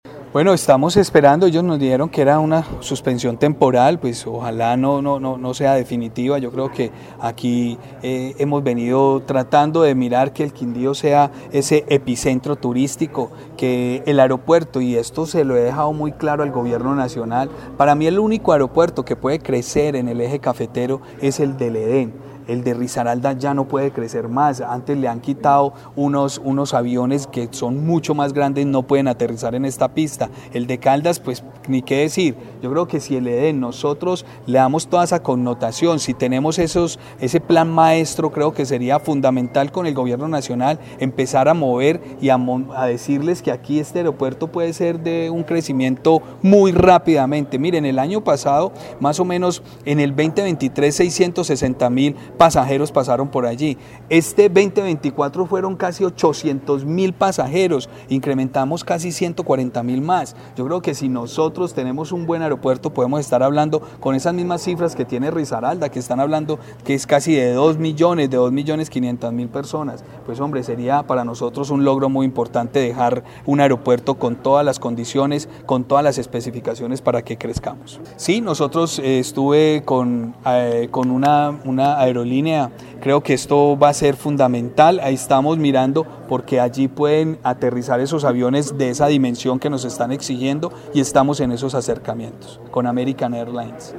Gobernador del Quindío